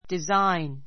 desi g n 中 A1 dizáin ディ ザ イン （ ⦣ g は発音しない） 名詞 ❶ デザイン , 図案, 模様 study design study design デザインを勉強する a carpet with a design of flowers a carpet with a design of flowers 花模様のついたカーペット The new theater is very modern in design.